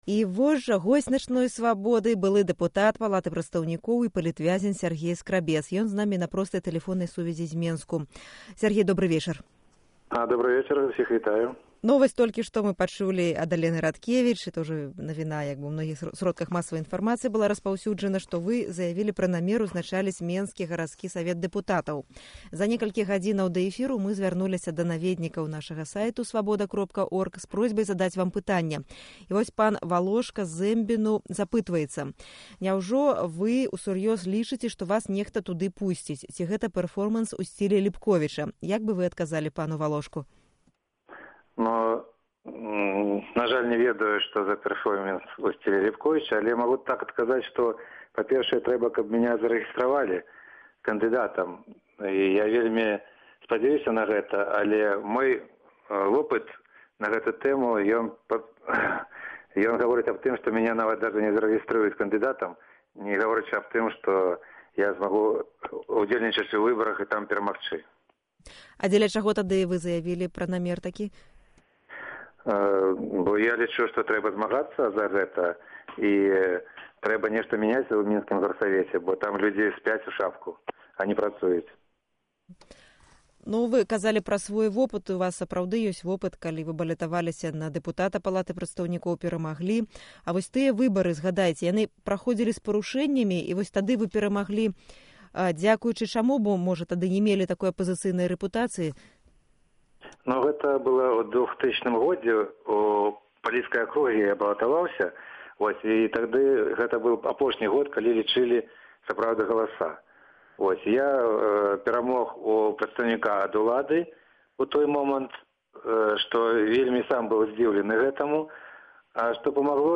Інтэрвію Сяргея Скрабца